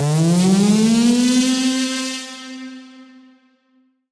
Index of /musicradar/future-rave-samples/Siren-Horn Type Hits/Ramp Up
FR_SirHornF[up]-C.wav